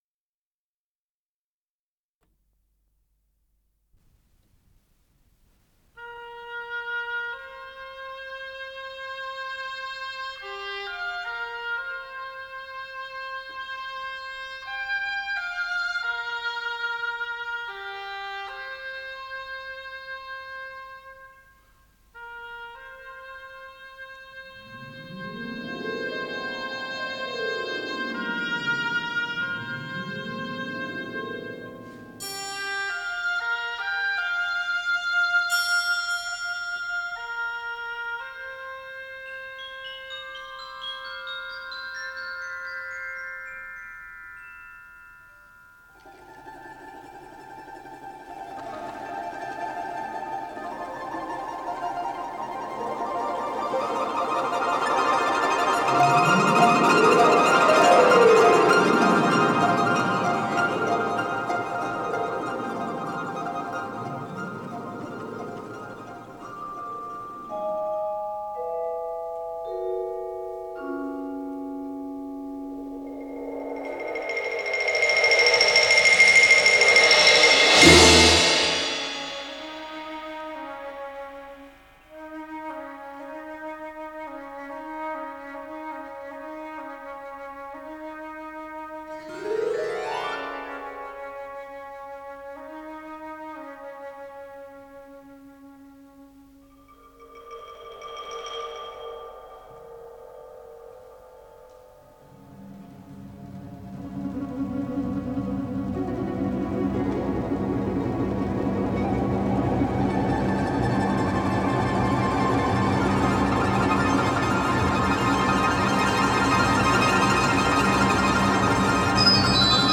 с профессиональной магнитной ленты
соль минор
ВариантДубль моно